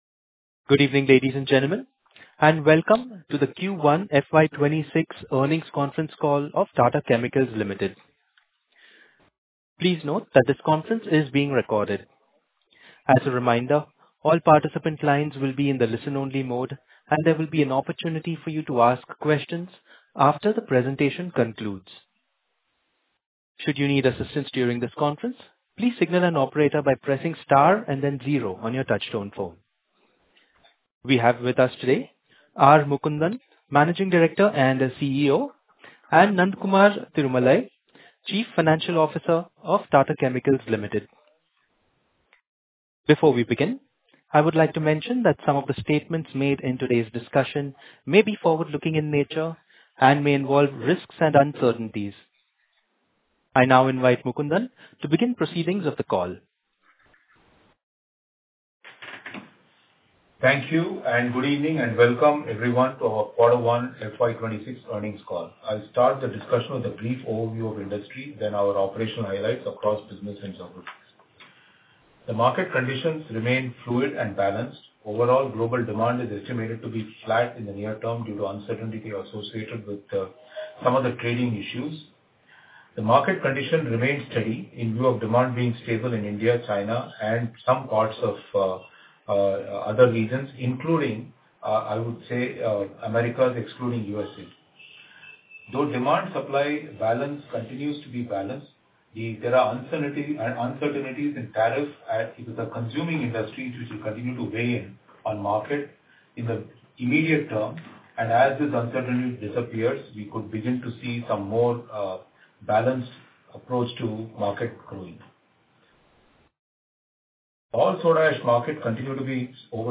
Investor Call - Audio Recording